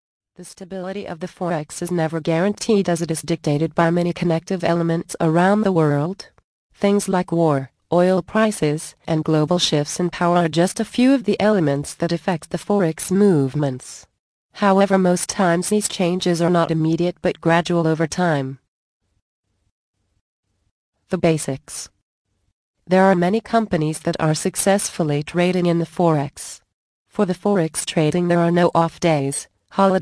Forex Foundry Audio Book + FREE Gift